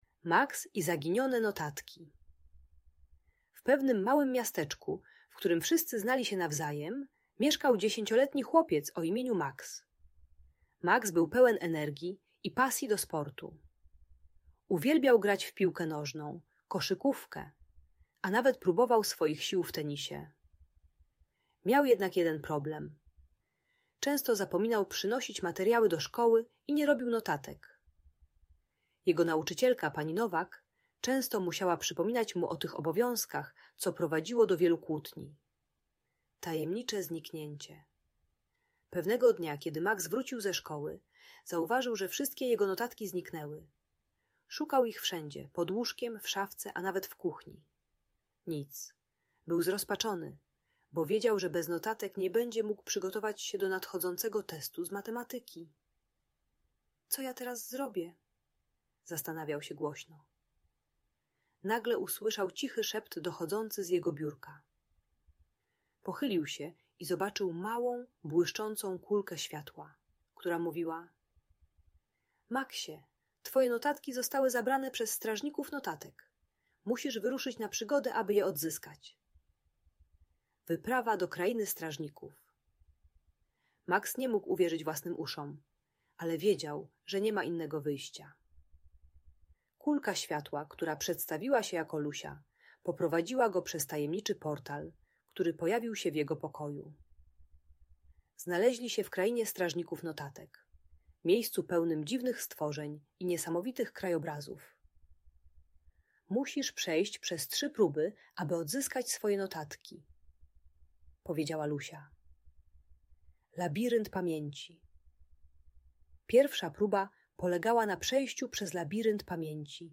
Maks i Zaginione Notatki - Szkoła | Audiobajka